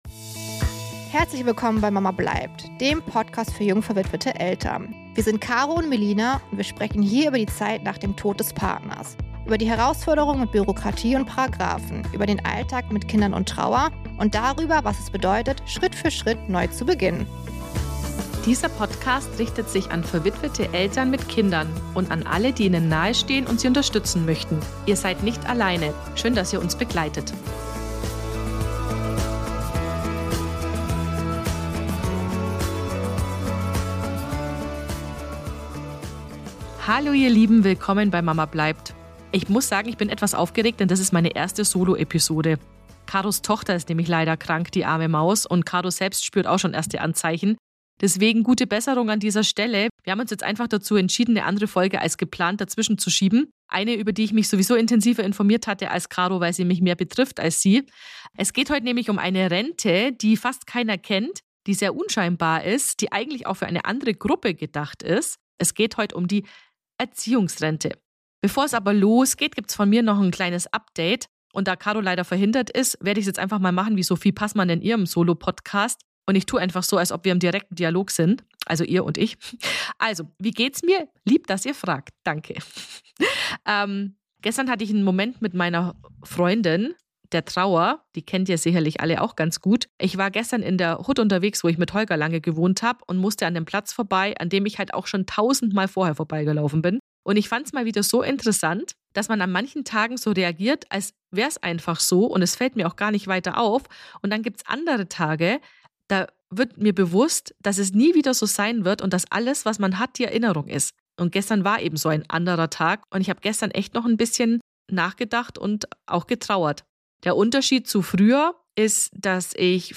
Heute bin ich ausnahmsweise solo unterwegs!